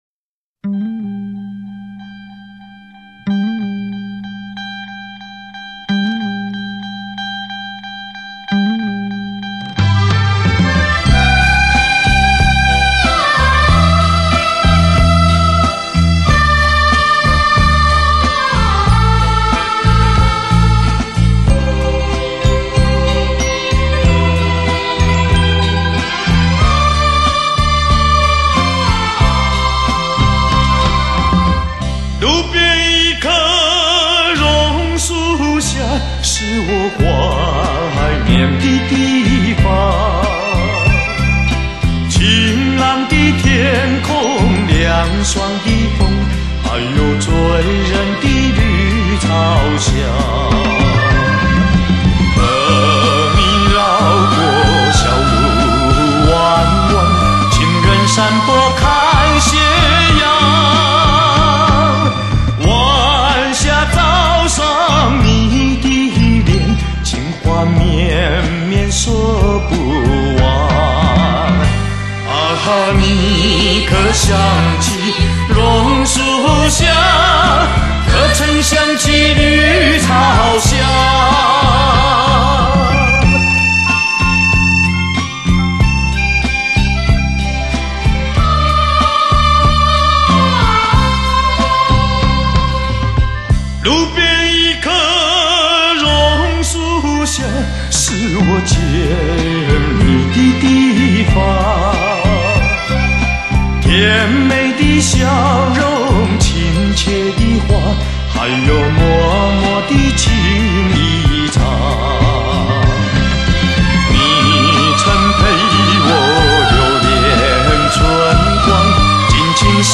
独树一格的唱腔，
怀念金曲 发烧天碟 日本重新制版
男声典范、感性演绎，